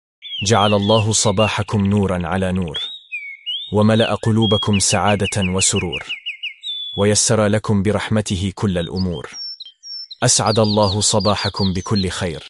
دعاء صباح يوم الاثنين دعاء الصباح بصوت جميل